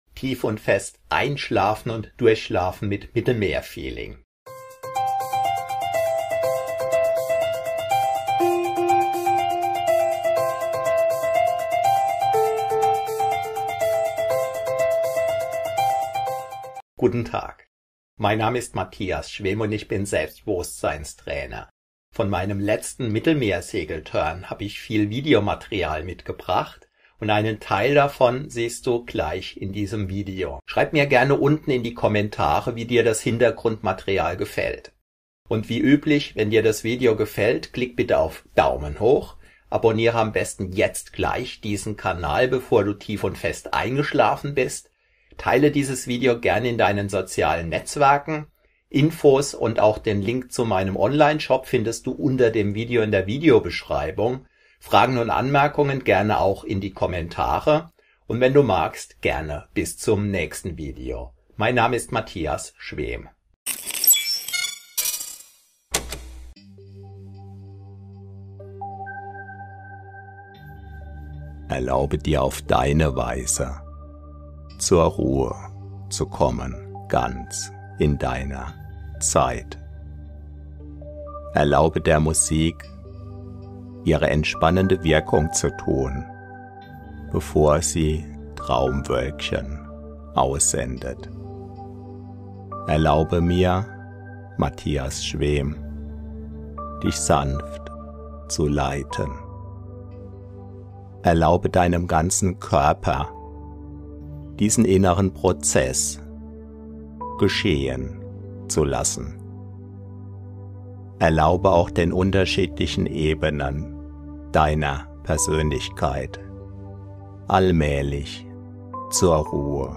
HypnoKing®: Hypnose zum Einschlafen und Durchschlafen - Hypnose zum Einschlafen Meditation Durchschlafen schnell tief schlafen mit Einschlafmusik 60 Minuten